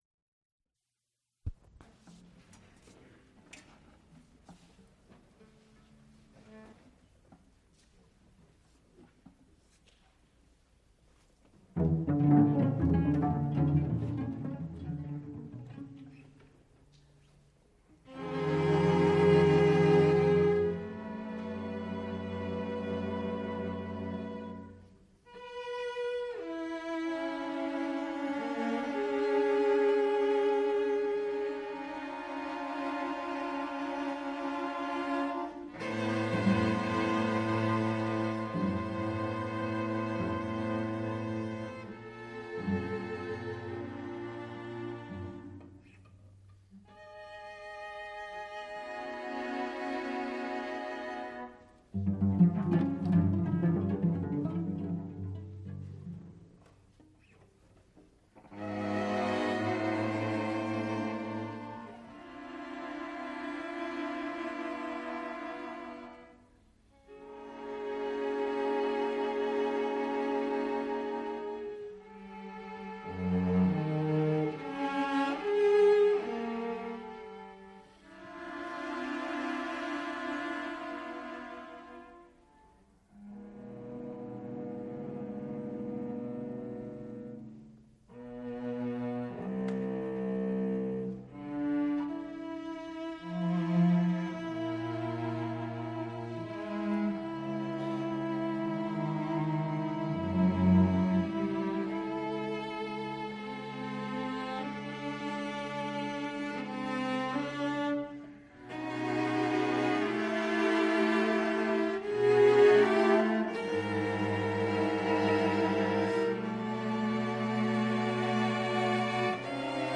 soprano
Instrumental ensembles Folk songs, Persian